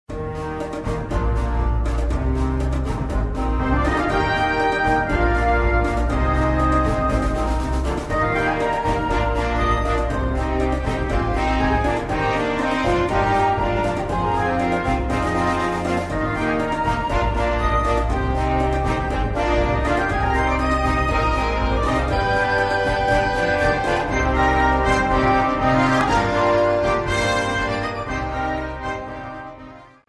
Rozrywkowa
rytmy afrykańskie